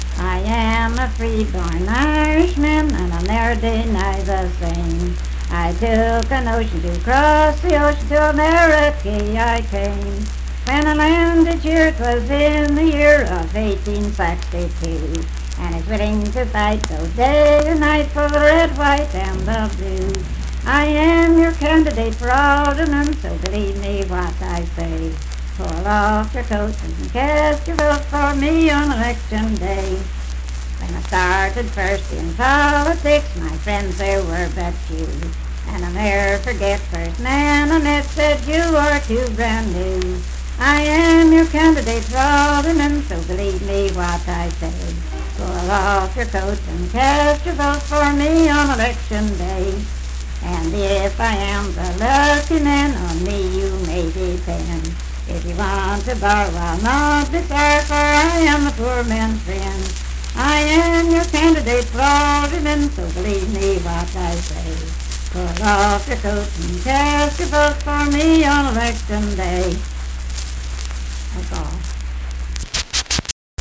Unaccompanied vocal music performance
Political, National, and Historical Songs
Voice (sung)